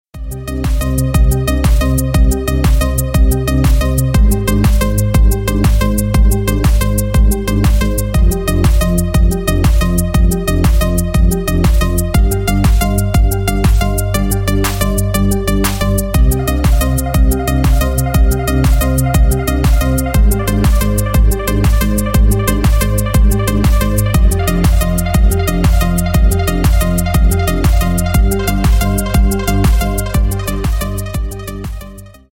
Рингтоны Без Слов
Рингтоны Электроника